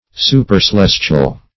Search Result for " supercelestial" : The Collaborative International Dictionary of English v.0.48: Supercelestial \Su`per*ce*les"tial\, a. [Pref. super- + celestial: cf. L. supercaelestis.] 1.
supercelestial.mp3